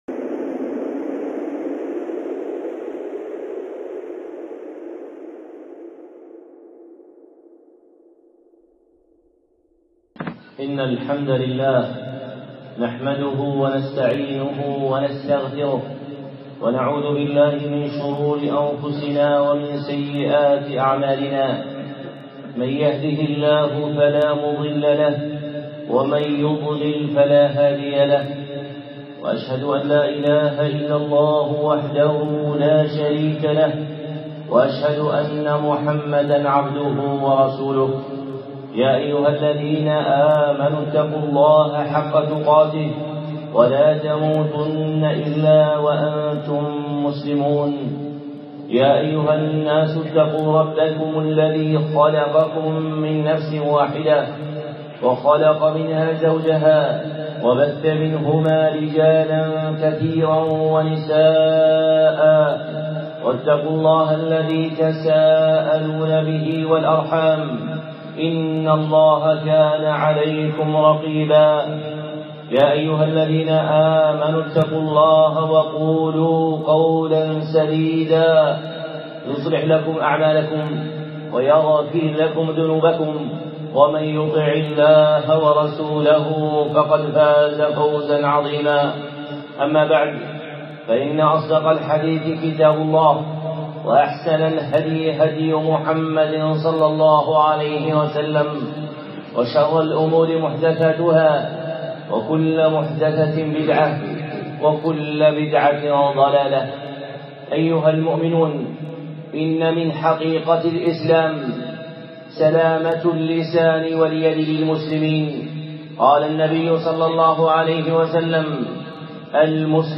خطبة (ويل لكل همزة لمزة) الشيخ صالح العصيمي